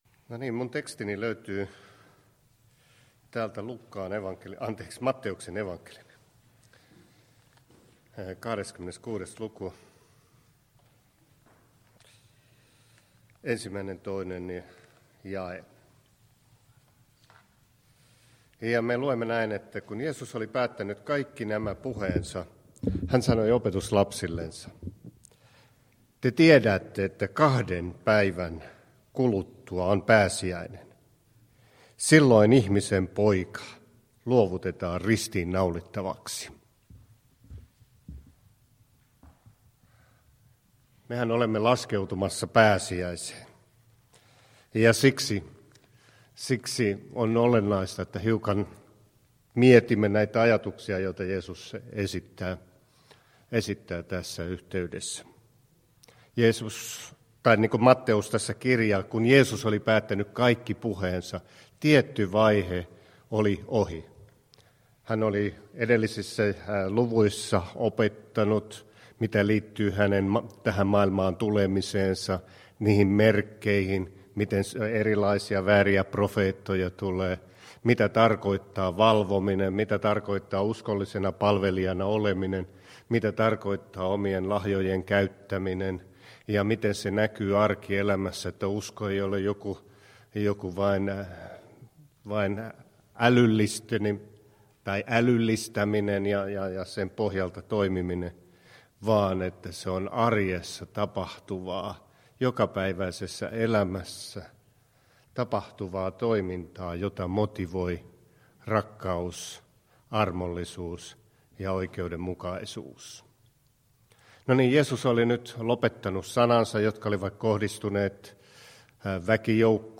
Jumalanpalveluksen puhe.mp3
21.3. Jumalanpalveluksen saarna